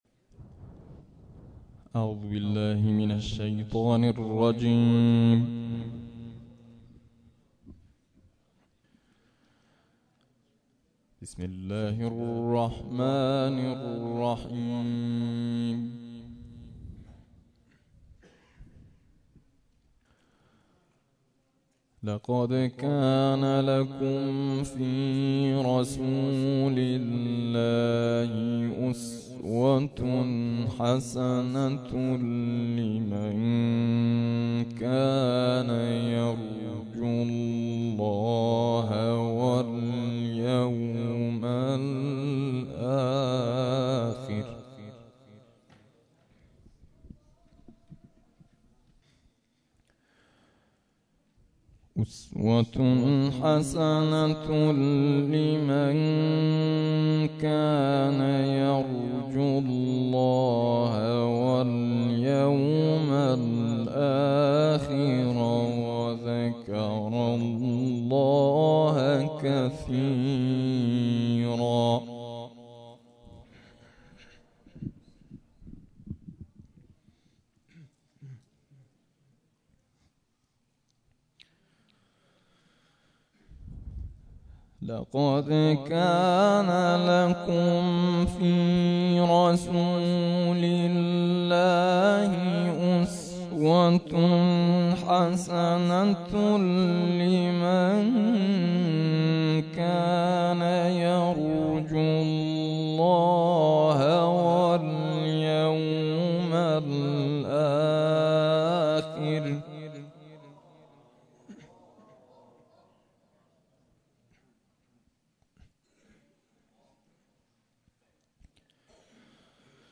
به گزارش خبرنگار فرهنگی باشگاه خبرنگاران پویا، مسجد الغدیر تهرانسر میزبان یکصد و پنجاه و سومین کرسی نفحات‌القرآن بود.